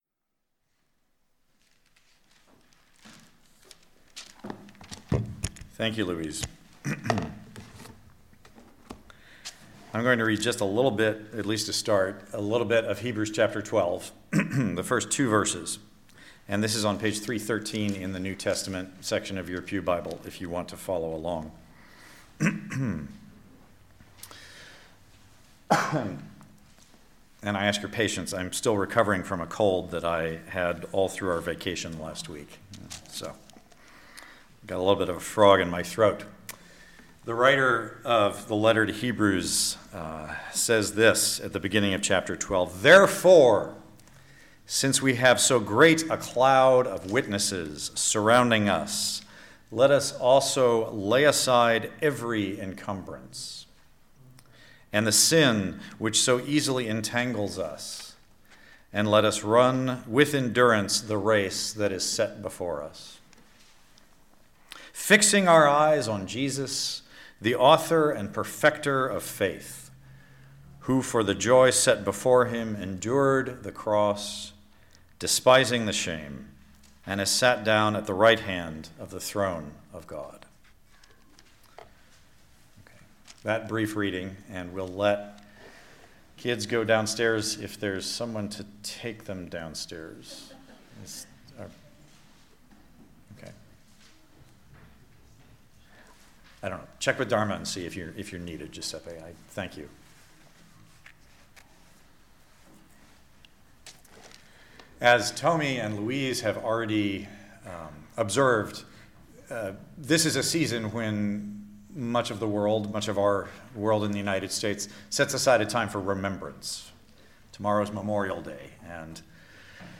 Listen to the most recent message from Sunday worship at Berkeley Friends Church.